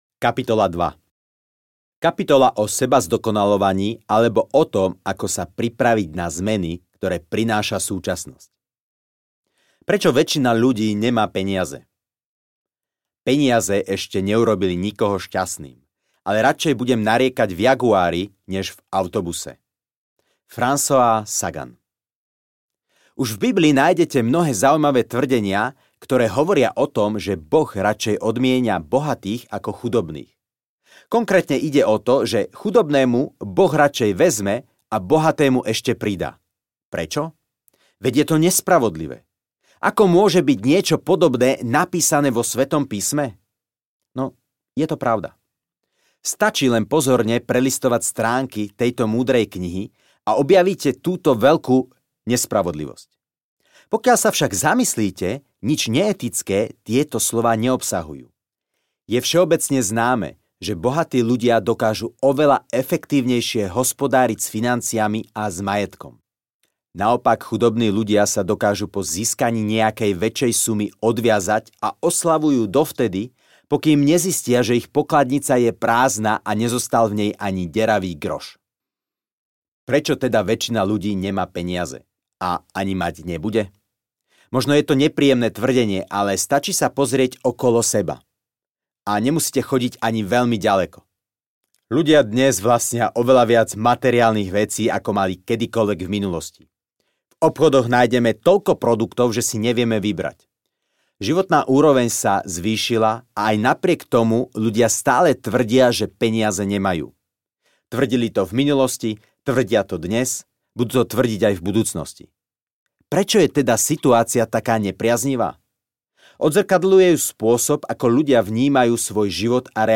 Mysli, Konaj, Zbohatni audiokniha
Ukázka z knihy